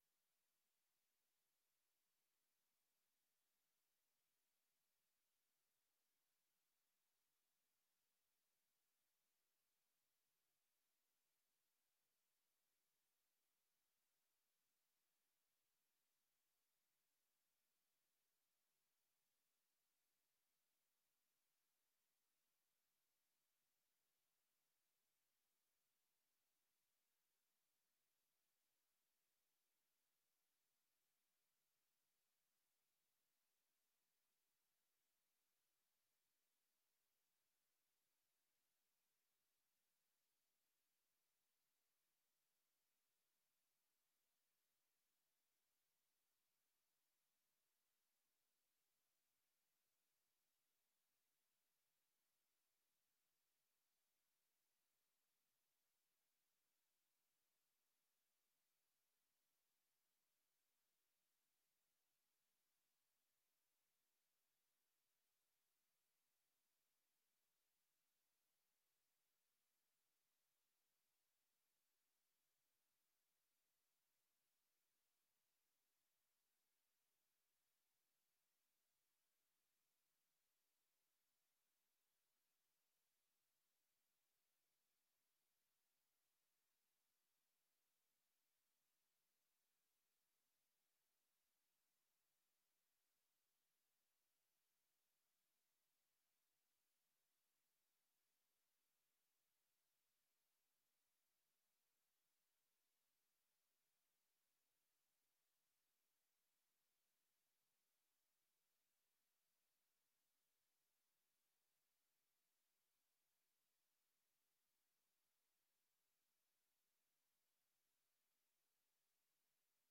Gemeenteraad 22 mei 2025 19:00:00, Gemeente Roosendaal
Locatie: Raadzaal